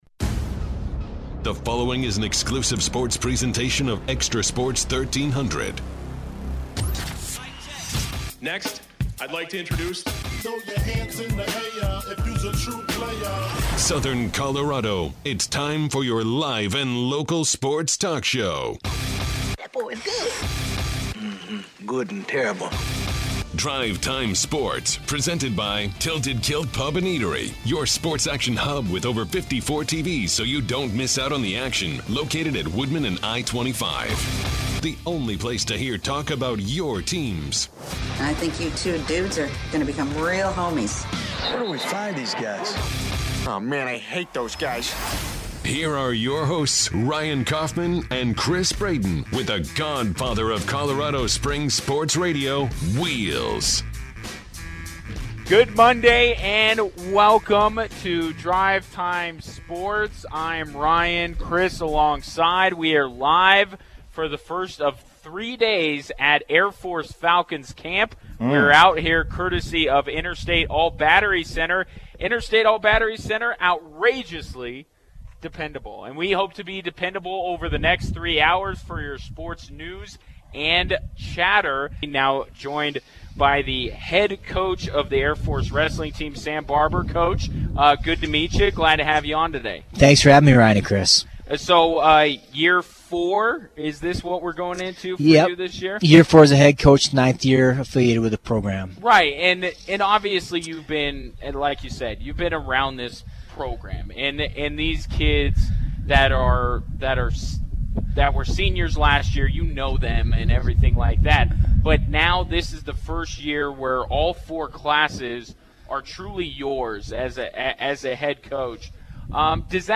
On Day One of DriveTime Sports at Falcons Camp